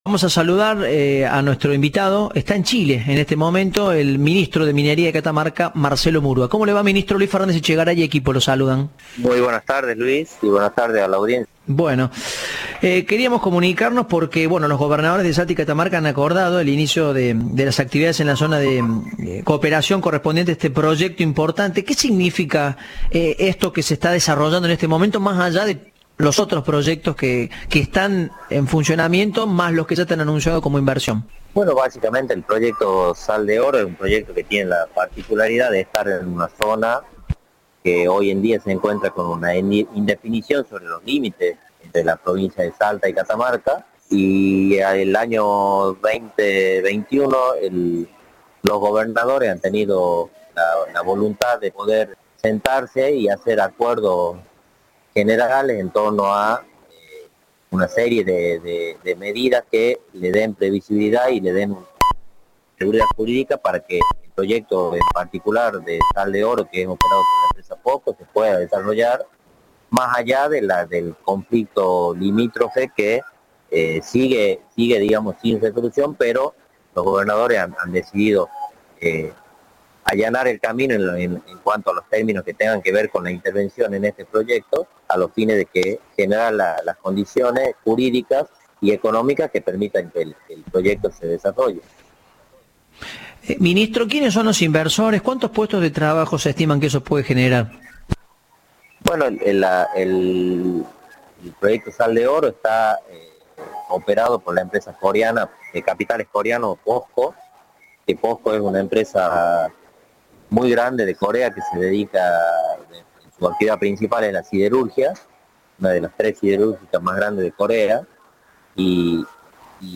En una reciente entrevista con Cadena 3, el ministro de Minería de Catamarca, Marcelo Murúa, anunció que los gobernadores de Catamarca y Salta acordaron el inicio de las actividades en el proyecto minero Sal de Oro, operado por la empresa coreana Posco.
Entrevista de "Informados, al regreso".